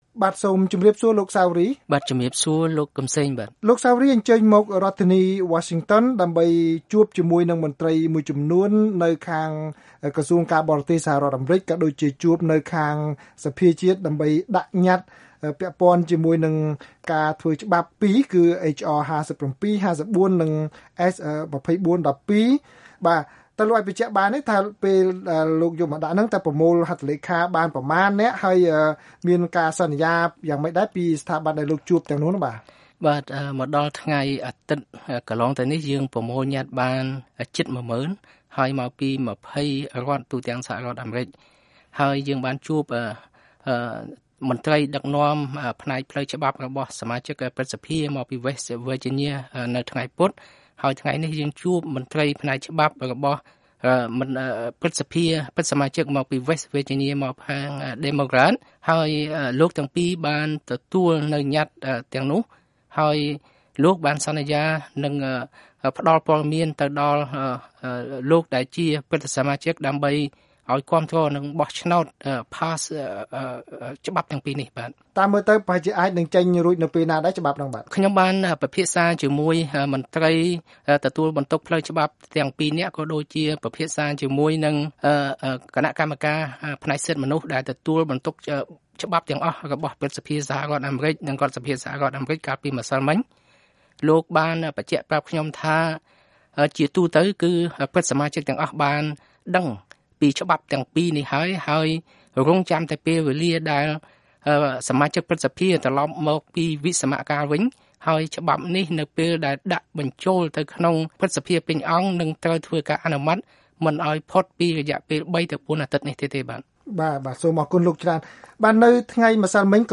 បទសម្ភាសន៍ VOA៖ សកម្មជនសង្គ្រោះជាតិស្នើឱ្យមានទណ្ឌកម្មធ្ងន់ធ្ងរលើបក្សពួកលោកហ៊ុន សែន